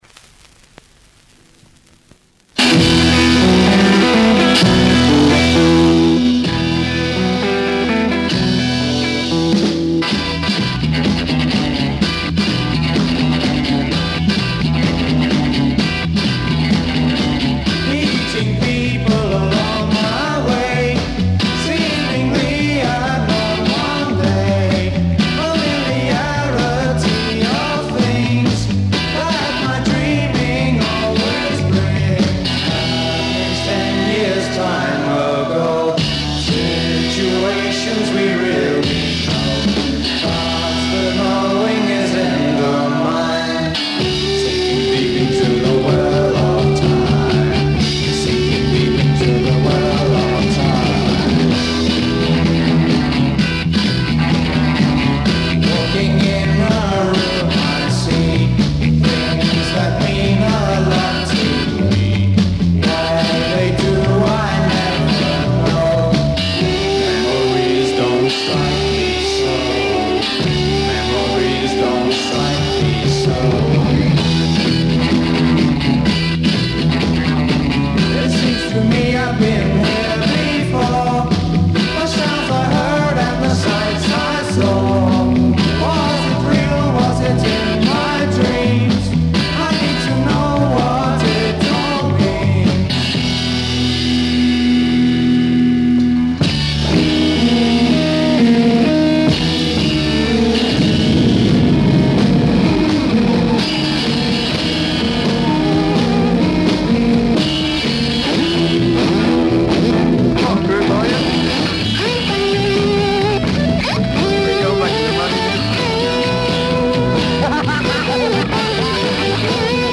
Le pattern rythmique est bien reconnaissable.
Même tonalité, tout !